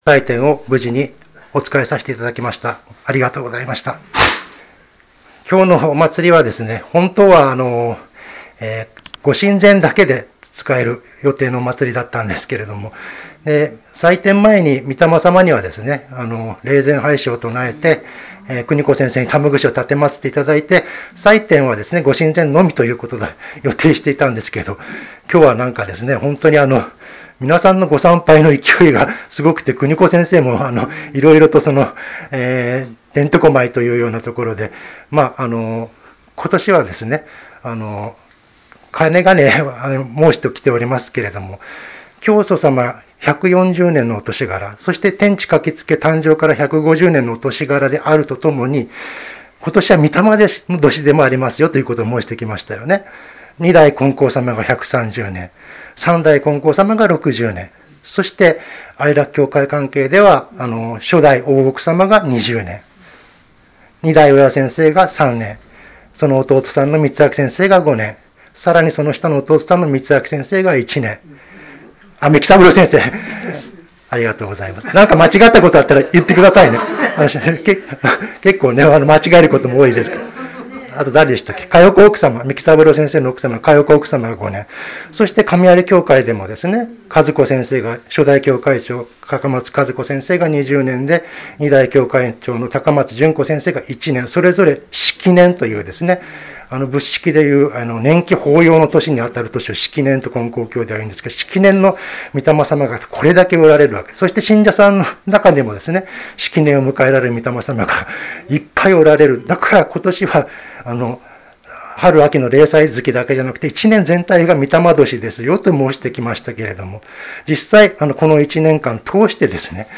越年祭教話